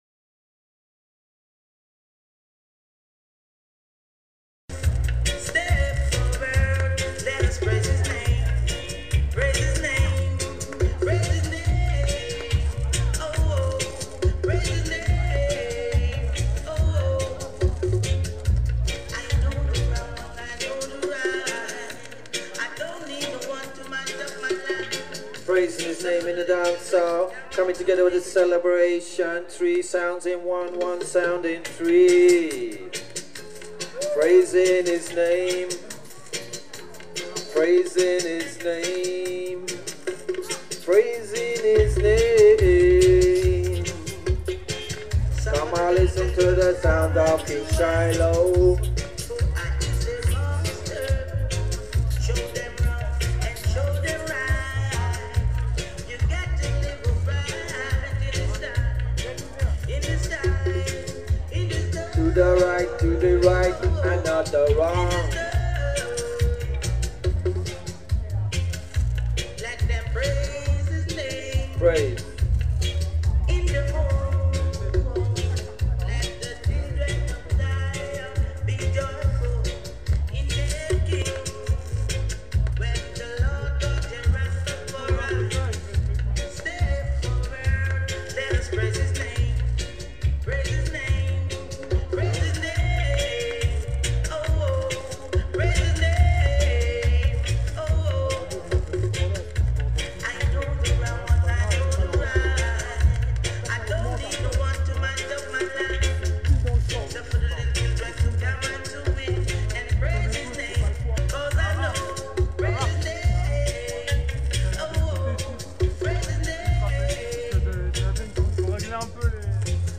THE QUEENSDAY ANNUAL FEST !!